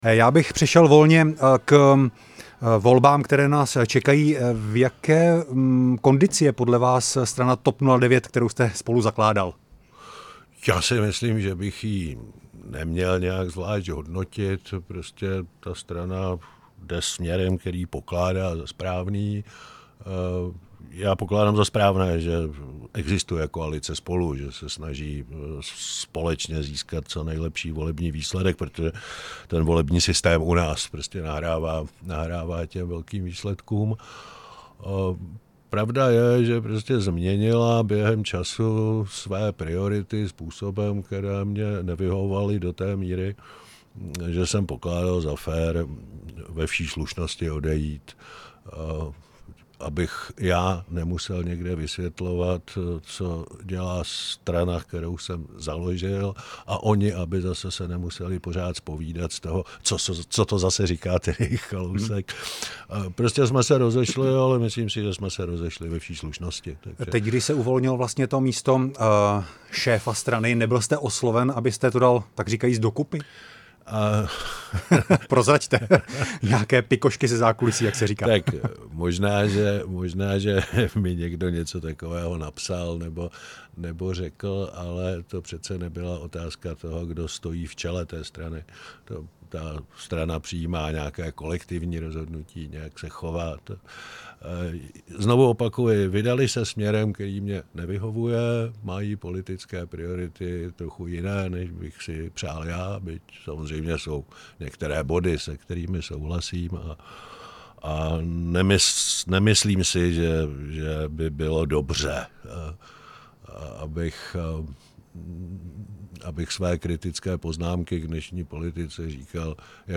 Třetí část rozhovoru s exministrem financí Miroslavem Kalouskem